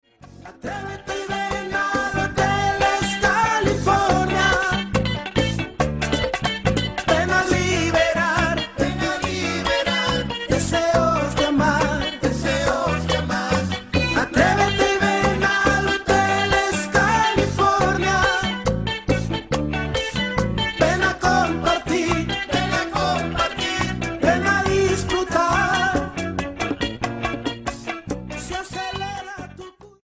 Bachata Charts - Februar 2011